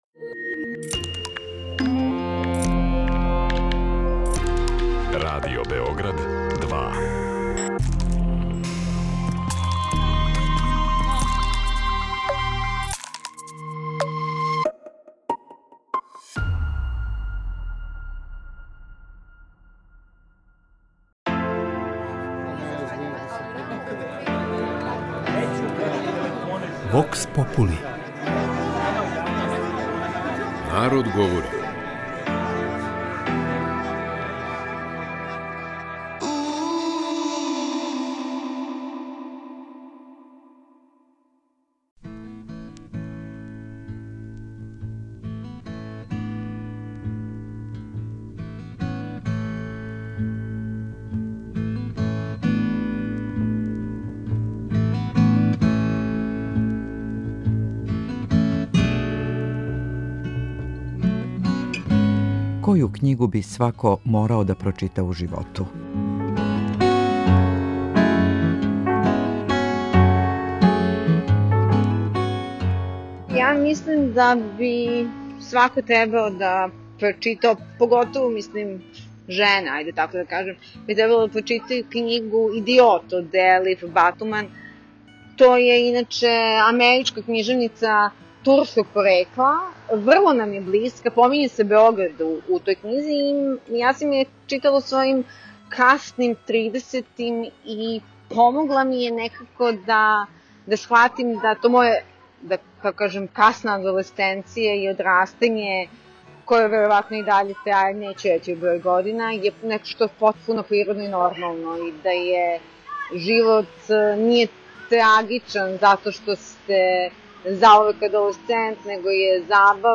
У данашњој емисији питали смо наше суграђане шта мисле ‒ коју књигу би свако морао да прочита у животу.
Вокс попули